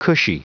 Prononciation du mot cushy en anglais (fichier audio)
Prononciation du mot : cushy